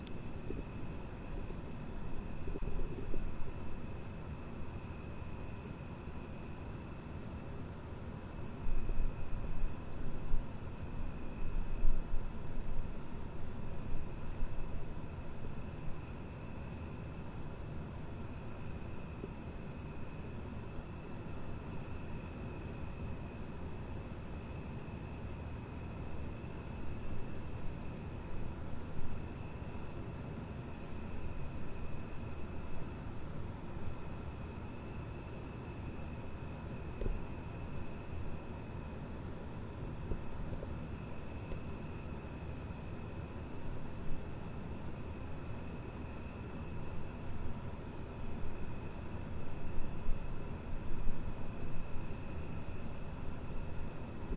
背景氛围
描述：基本的背景噪音和氛围
Tag: 背景 噪音 环境